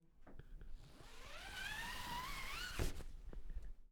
Magic-And-Spell-Sound-Effects-finger-dragged-across-shower-curtain – Free Music Download For Creators
Magic-And-Spell-Sound-Effects-finger-dragged-across-shower-curtain.mp3